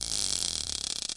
免费的啤酒开瓶和浇灌
描述：嗨，两段打开一瓶黑啤酒（德国Köstrizer啤酒）并将其倒入玻璃杯的录音，录音是用两个不同的玻璃杯完成的。
声道立体声